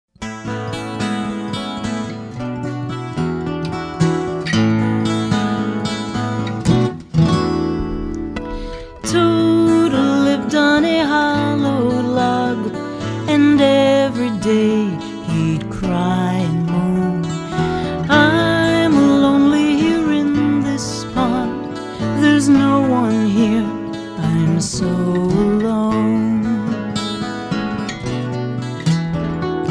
▪ The full vocal track.